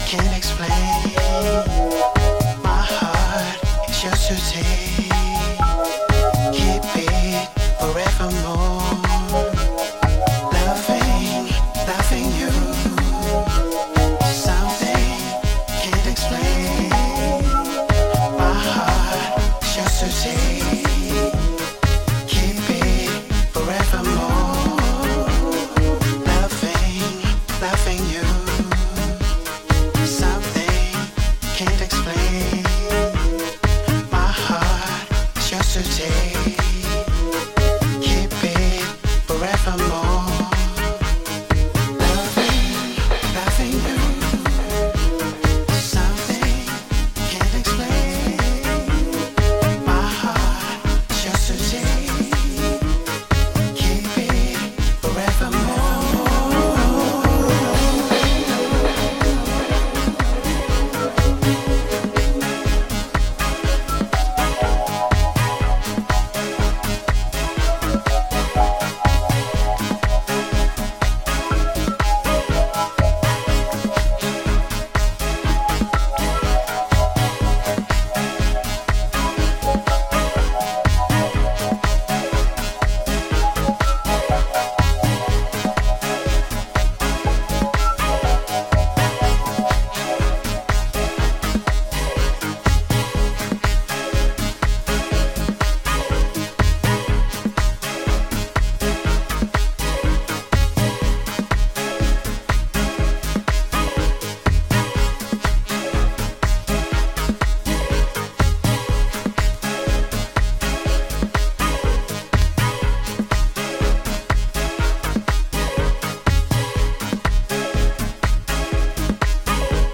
原曲のグルーヴを抑えめにしながら、より繊細でしっとり浸透していくフィーリングを強めていったそちらももちろん良し。
Dub Mix